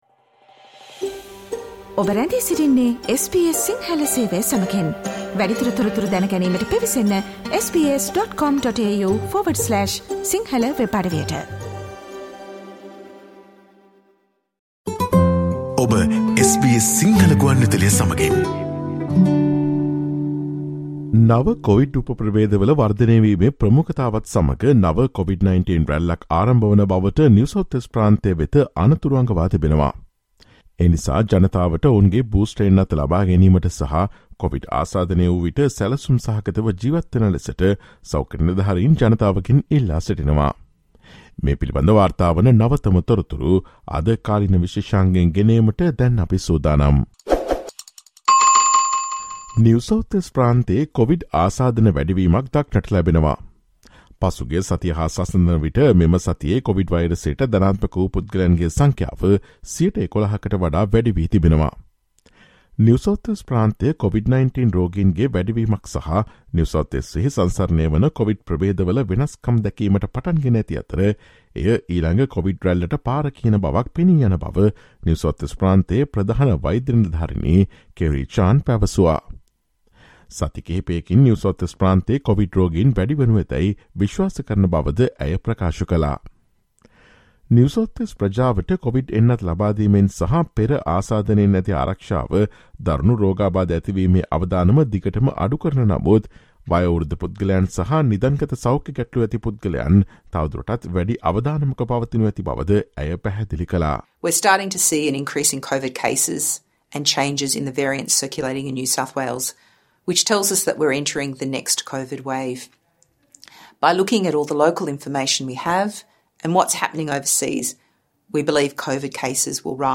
New South Wales has been warned that a fresh COVID-19 surge is beginning, prompted by the growing prominence of new sub-variants. Listen to the SBS Sinhala Radio's current affairs feature on Friday 04 November.